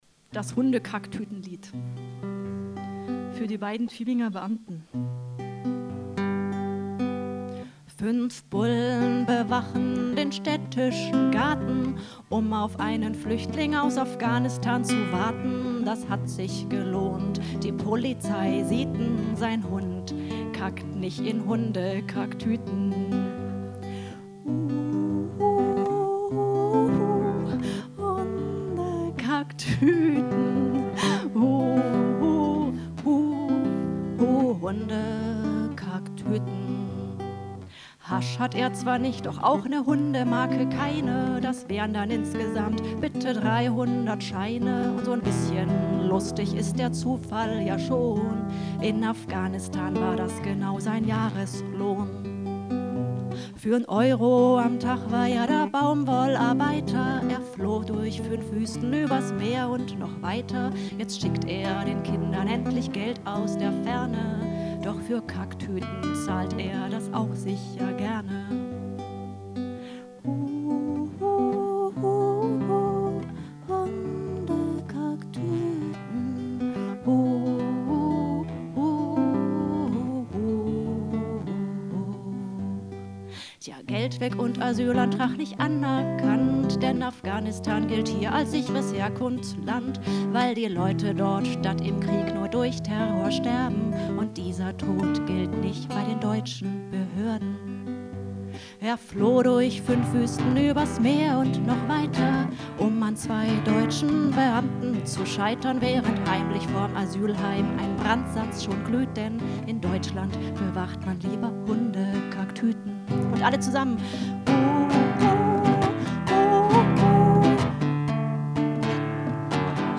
Events, Live-Übertragungen
BENEFIZ-COMEDY-MIXED-SHOW